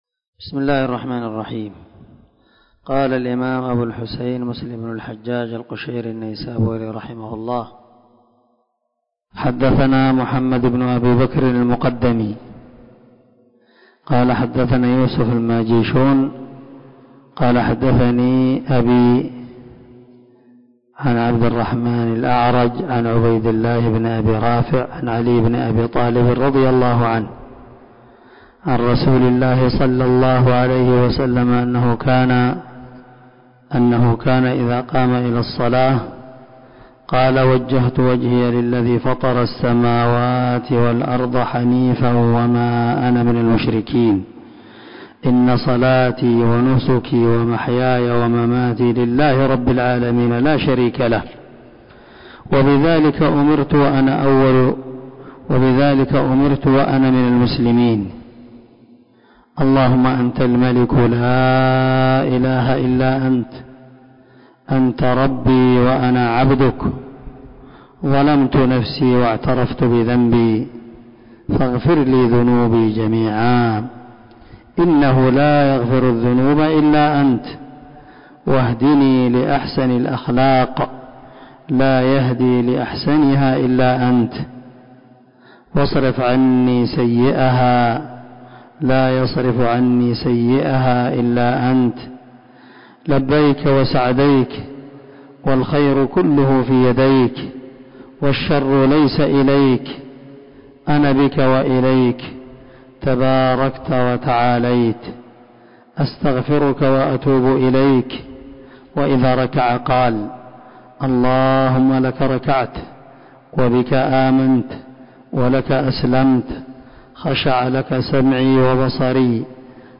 469الدرس 37 من شرح كتاب صلاة المسافر وقصرها حديث رقم ( 771 ) من صحيح مسلم
دار الحديث- المَحاوِلة- الصبيحة.